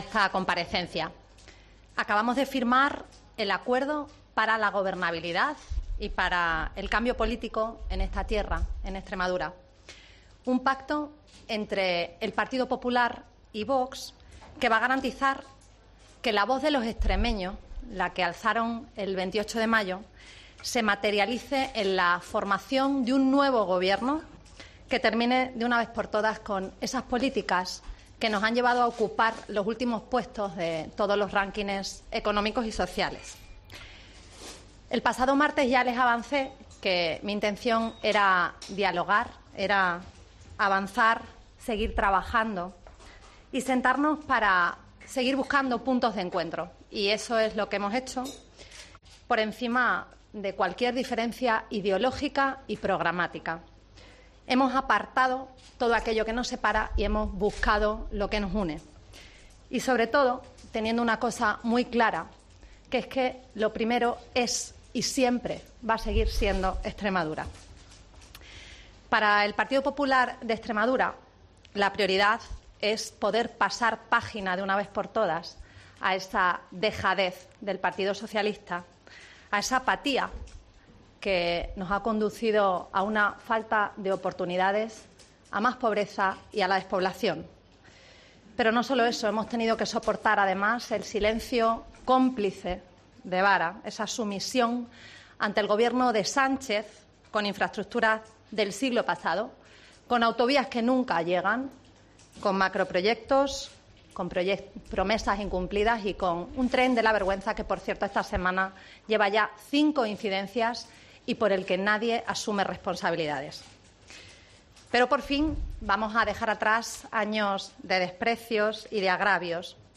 La presidenta del PP en Extremadura ha comparecido en rueda de prensa junto a Ángel Pelayo Gordillo, tras su acuerdo con Vox